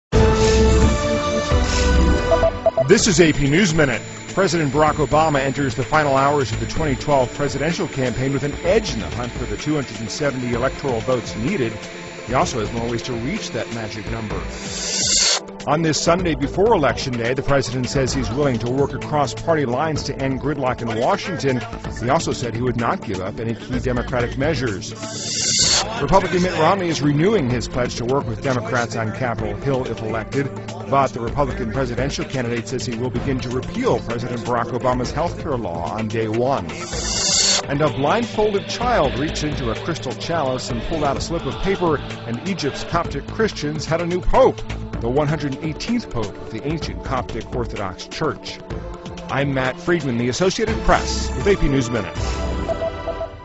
在线英语听力室美联社新闻一分钟 AP 2012-11-06的听力文件下载,美联社新闻一分钟2012,英语听力,英语新闻,英语MP3 由美联社编辑的一分钟国际电视新闻，报道每天发生的重大国际事件。电视新闻片长一分钟，一般包括五个小段，简明扼要，语言规范，便于大家快速了解世界大事。